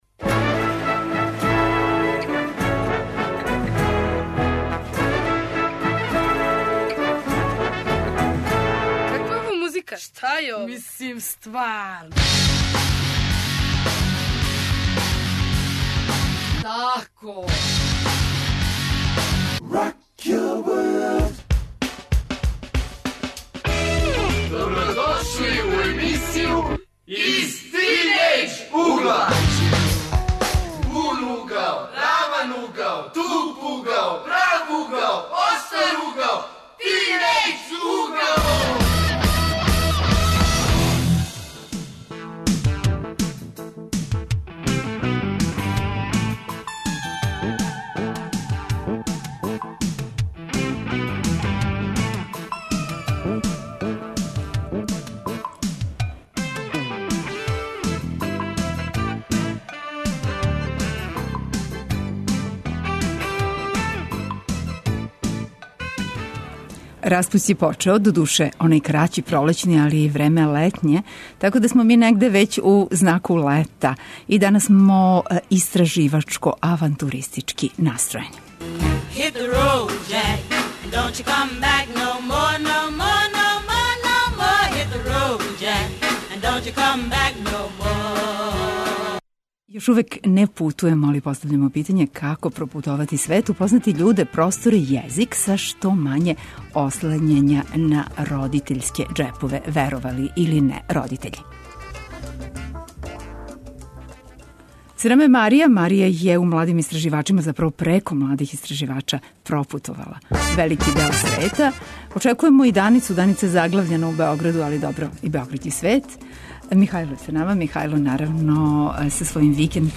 У данашњој емисији смо авантуристички настројени - истражујемо начине како пропутовати и упознати свет са што мање ослањања на родитељске џепове. У гостима су нам средњошколци и студенти којима то већ годинама успева.